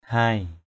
/haɪ/ 1.